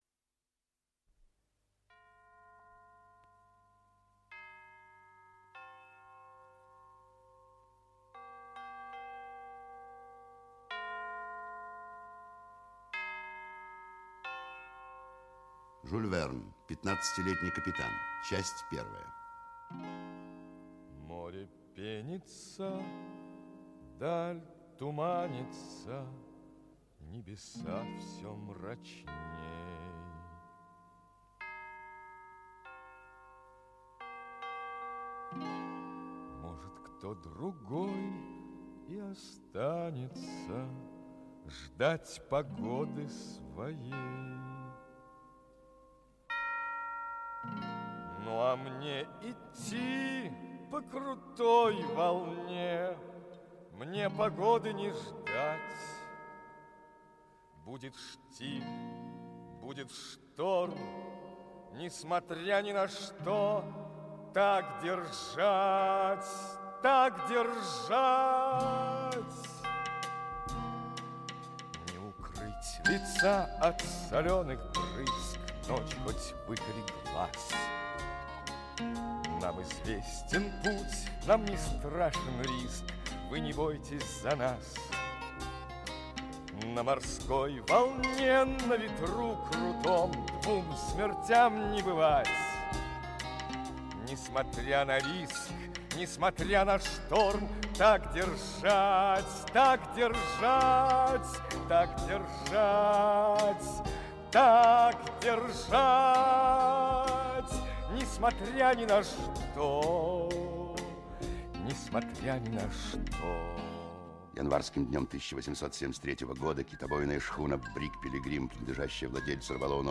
Аудиокнига Пятнадцатилетний капитан (спектакль) | Библиотека аудиокниг
Aудиокнига Пятнадцатилетний капитан (спектакль) Автор Жюль Верн Читает аудиокнигу Олег Табаков.